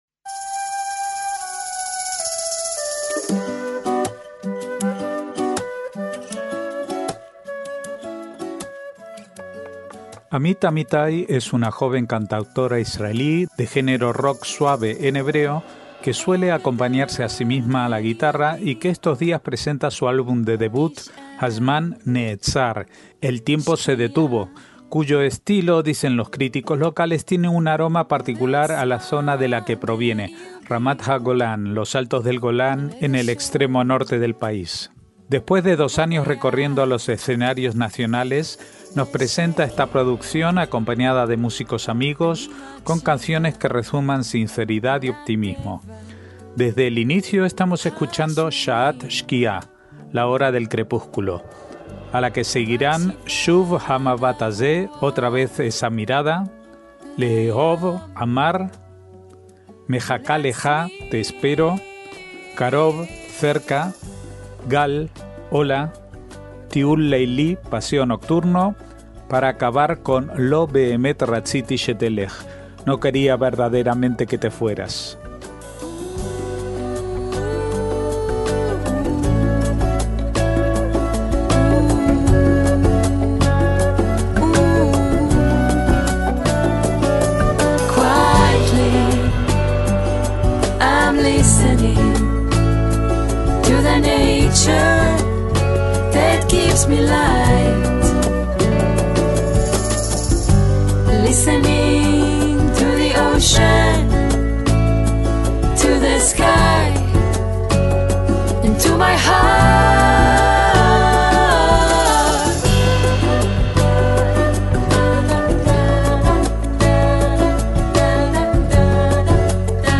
MÚSICA ISRAELÍ
rock suave
guitarra